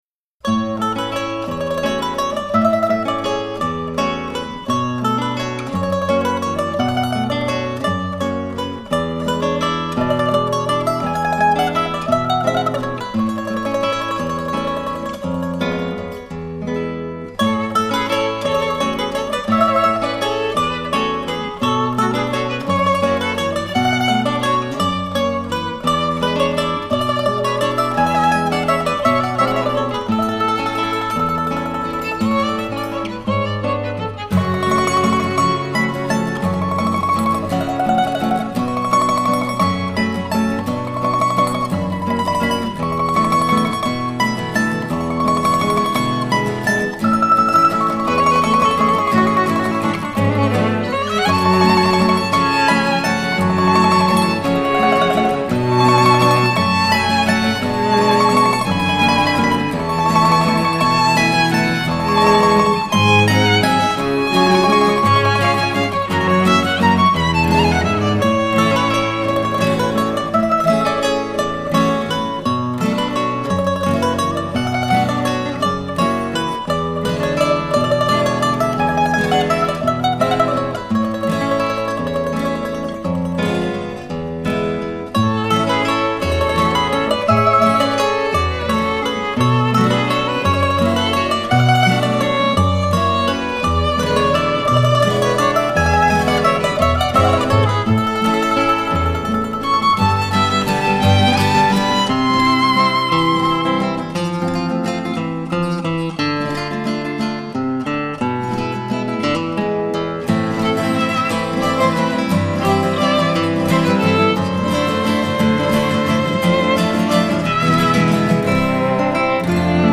活泼、富生命力的波斯菊 绽放出音乐芬芳